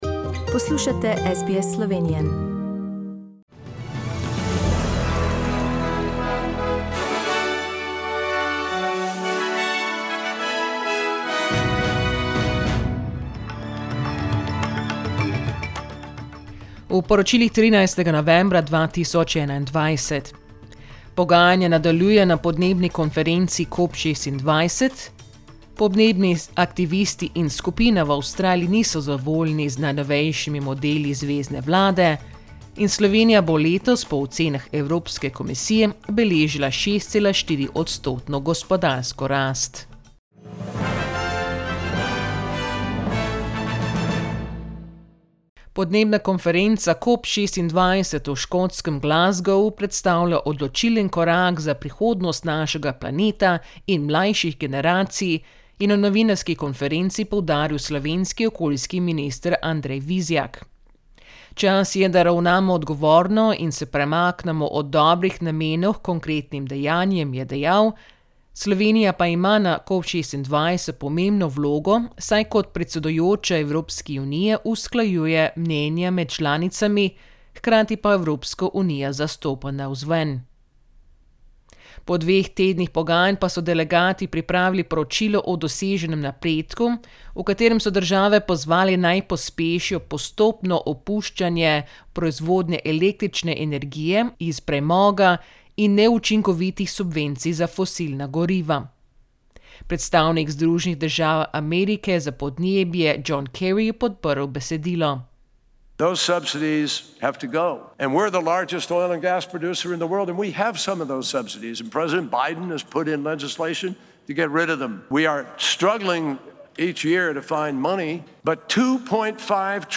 Poročila Radia SBS v slovenščini 13.novembra